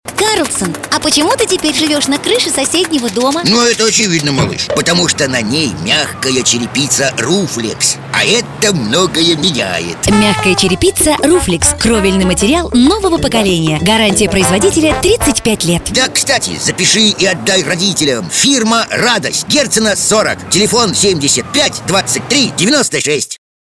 Игровой ролик 6